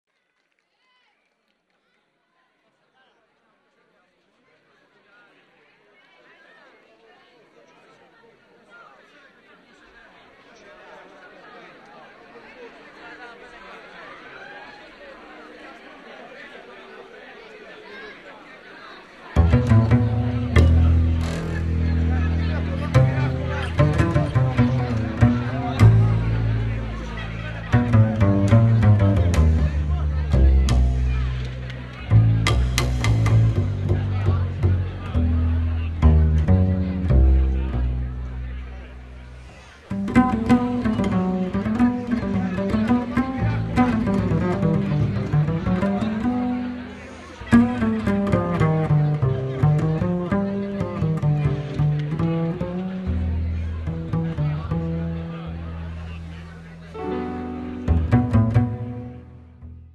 piano
saxophone
trumpet
vocals
bass
percussions
drums